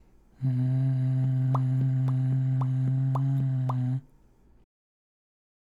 ※見本のパーの声(口パクパク編)
「んー」と鼻から声を出しながらの口の開閉をやってもらいます。